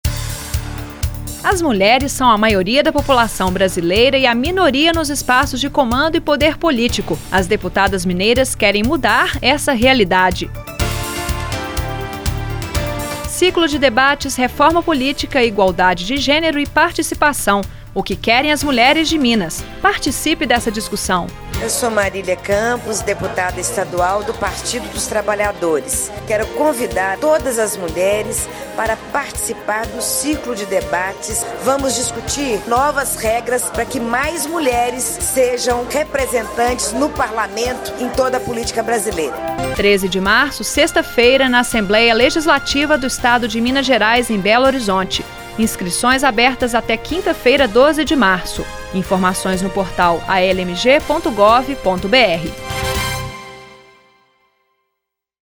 Ouça o convite da deputada Marília Campos, PT